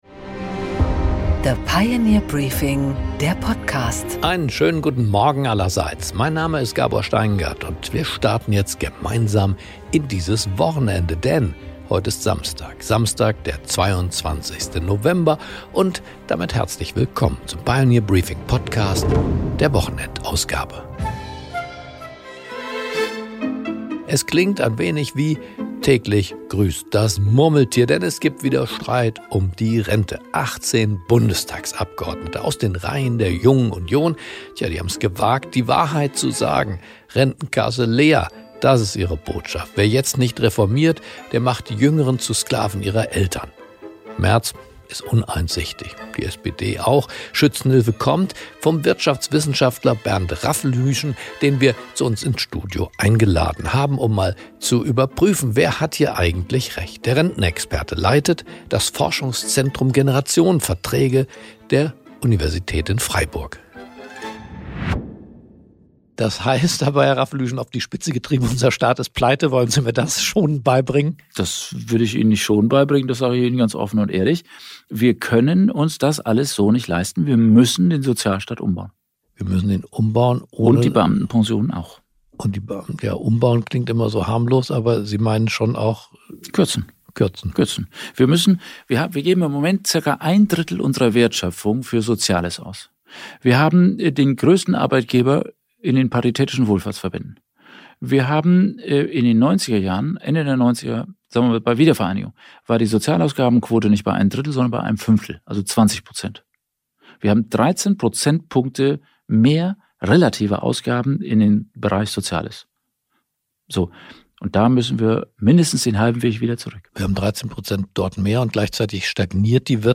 Gabor Steingart präsentiert die Pioneer Briefing Weekend Edition